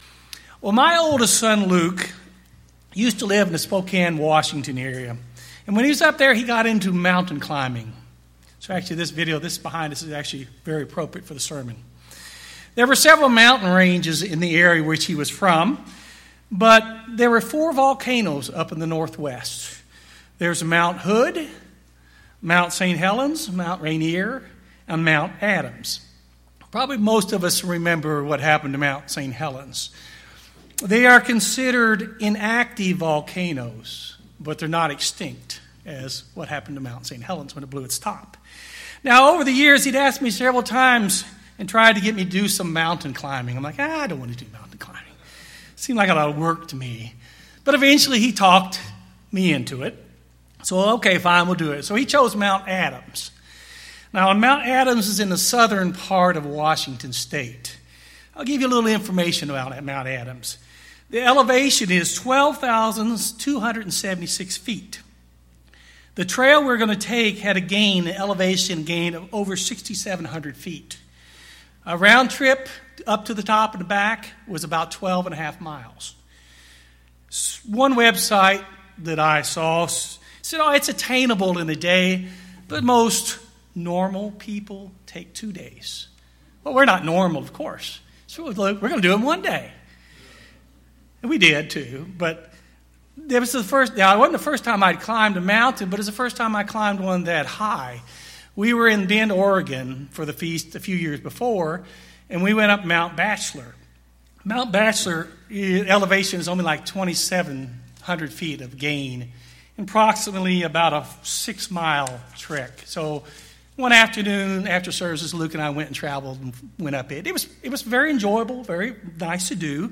Our journey to God’s Kingdom compares closely to the difficult climb required to reach the summit of a mountain. In this sermon, focus on important similarities and reflect on what we can do to prepare, to endure and to be encouraged.